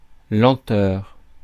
Ääntäminen
Ääntäminen France: IPA: [lɑ̃.tœʁ] Haettu sana löytyi näillä lähdekielillä: ranska Käännös 1. lēnums {m} Suku: f .